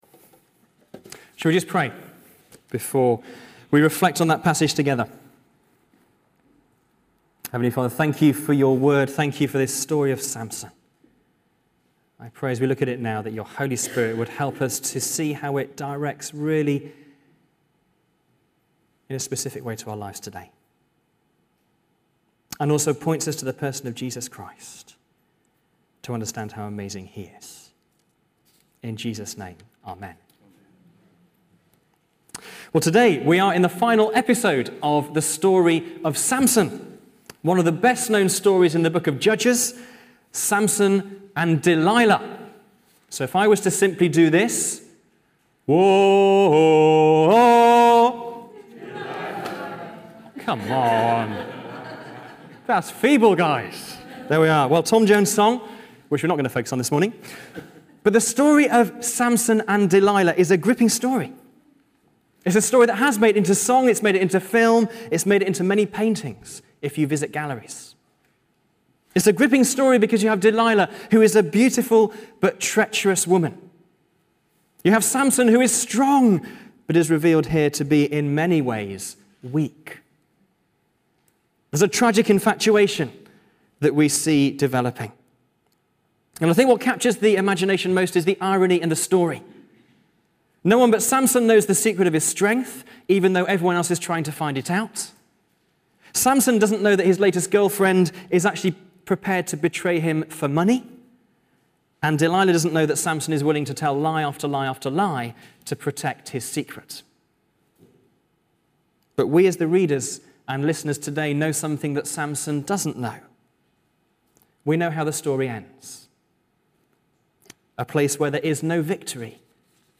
Sermon_5Oct2014.mp3